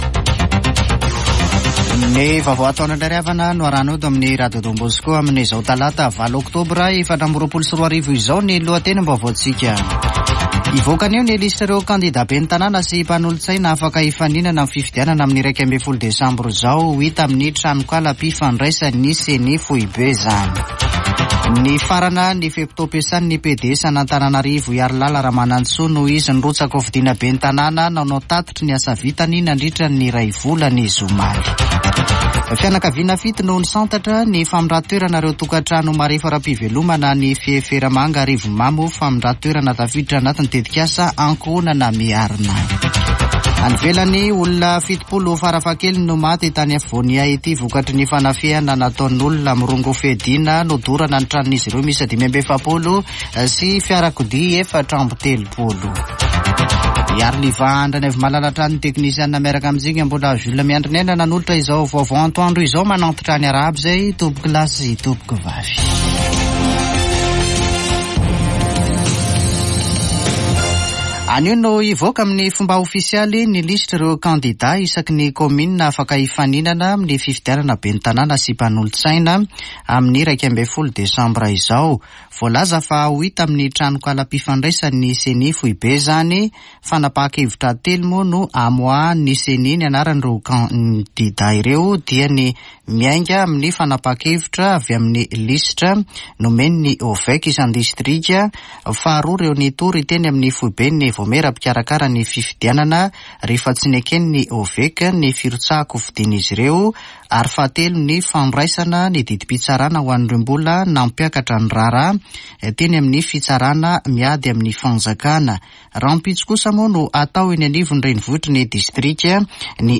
[Vaovao antoandro] Talata 8 oktobra 2024